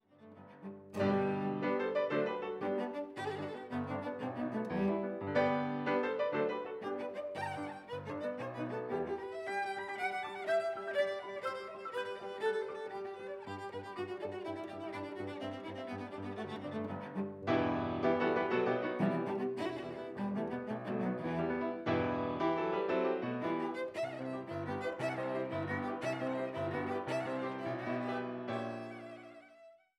Cello und Klavier
Kammermusik aus Böhmen